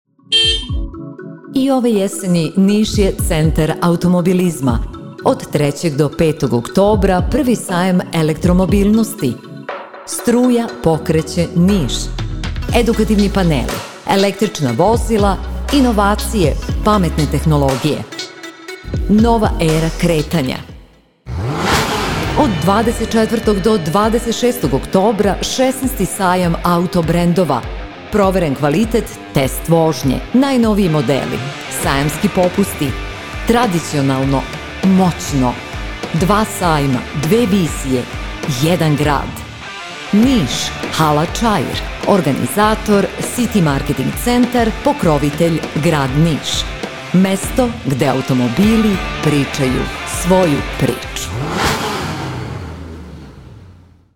PROMO SPOTOVI
SESB-Radio-FINAL.mp3